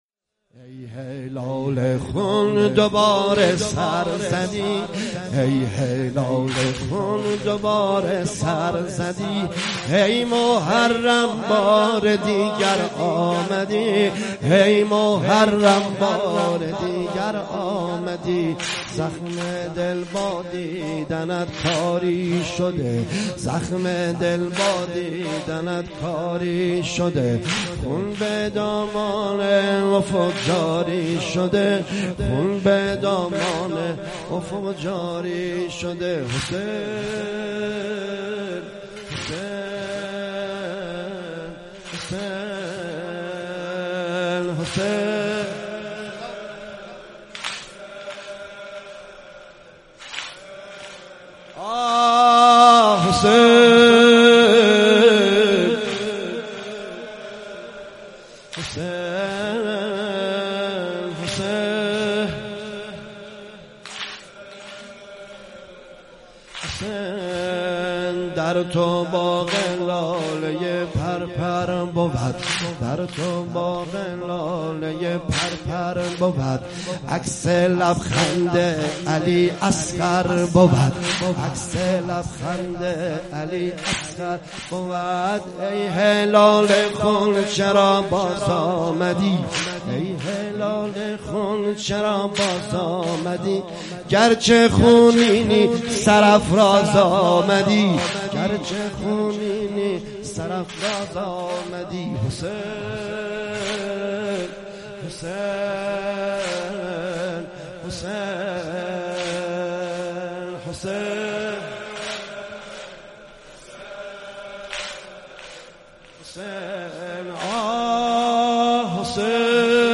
صوت مداحی های شب دوم محرم سال ۱۳۹۷ نریمان پناهی را در ادامه می توانید مشاهده و دانلود نمایید.